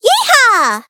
Worms speechbanks
flawless.wav